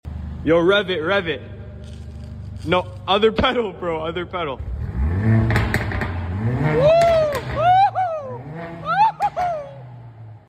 What 4 banger sounds better? sound effects free download